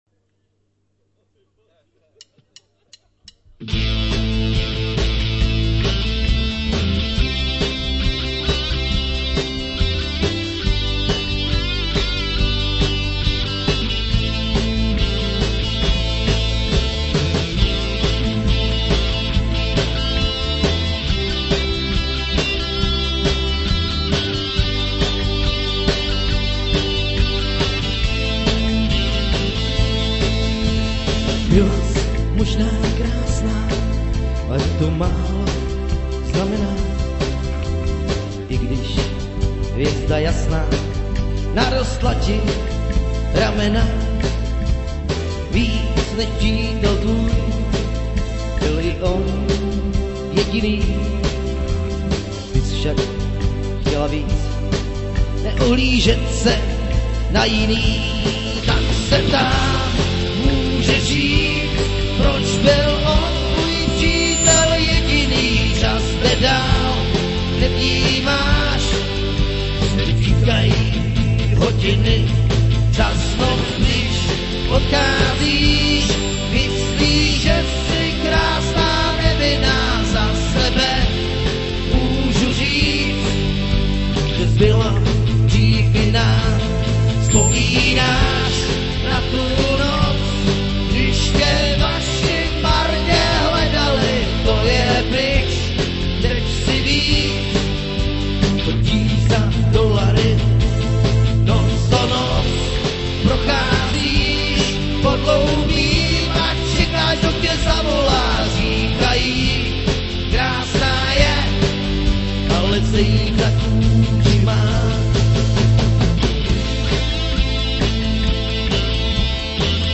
HUDEBNÍ UKÁZKY - Naše úplně první živé nahrávky z Tylova paloučku 10.5.2008
Upozorňujeme že všechny níže uvedené nahrávky jsou nahrávány na mobil, takže kvalita není profi